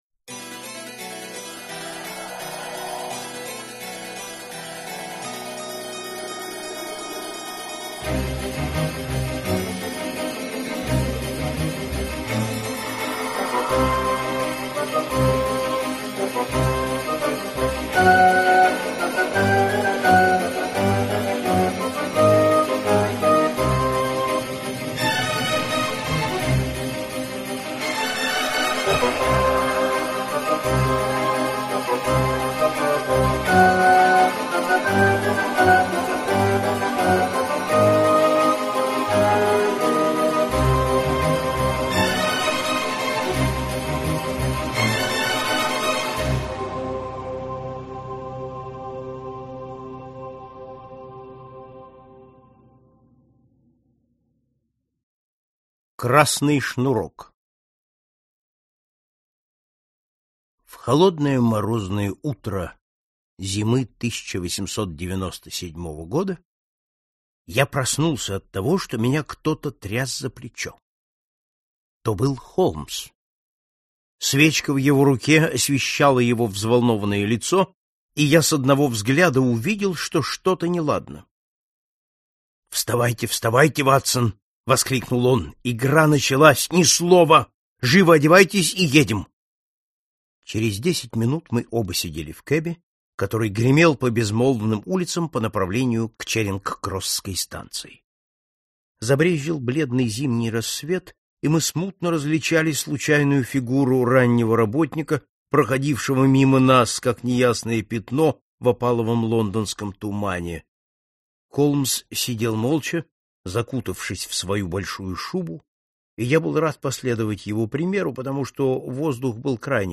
Аудиокнига Возвращение Шерлока Холмса | Библиотека аудиокниг